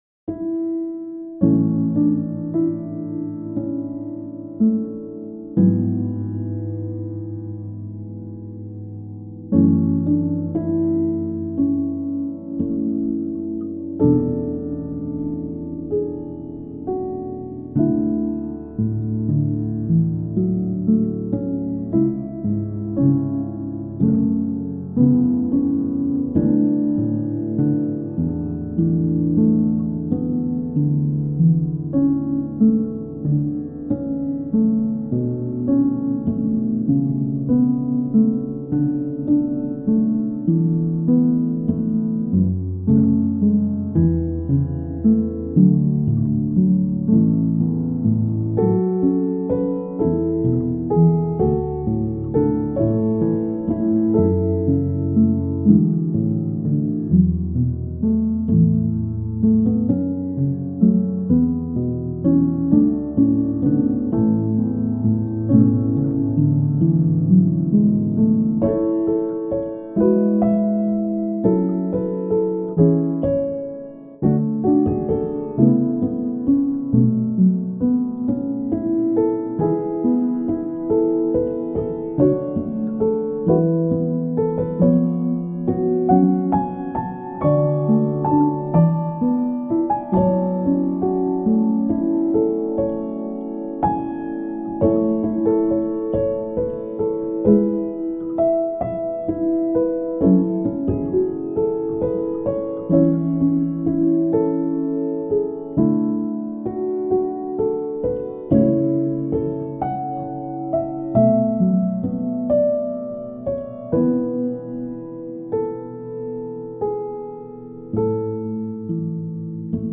ピアノ 寝落ち 穏やか